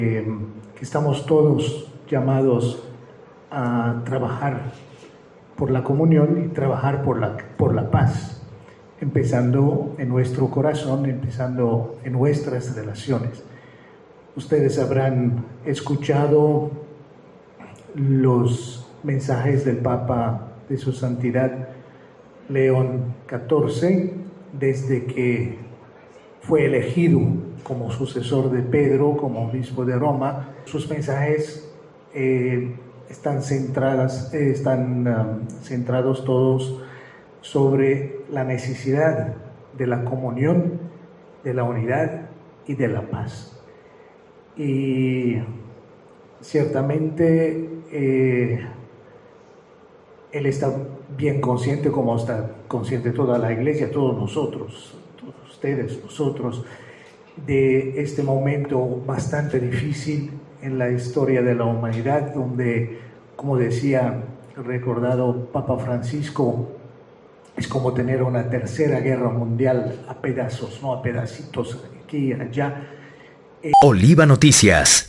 Previo a la celebración eucarística, el Nuncio Apostólico Joseph Spiteri se pronunció por un mensaje de paz, en torno a los hechos que afectan a migrantes en Estados Unidos y también la Guerra.
joseph_spiteri-nuncio_apostolico.mp3